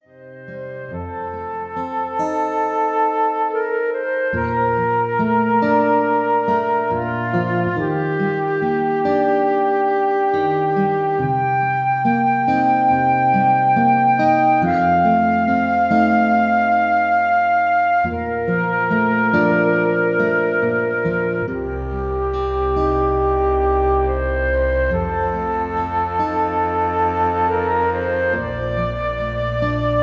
Classical quartett, flute, violin,guitar,bass